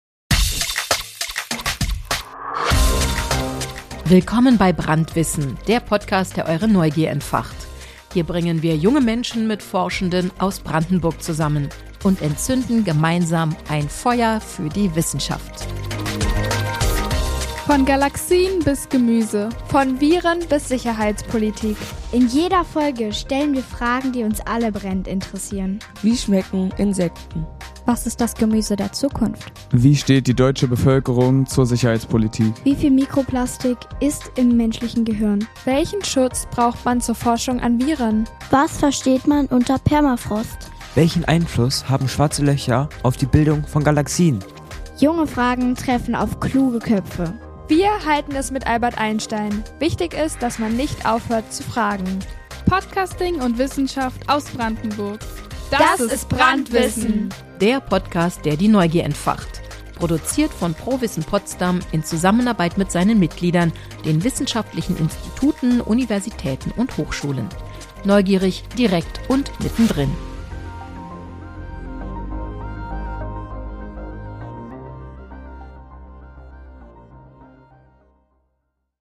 Junge Fragen an Forschende aus Brandenburg.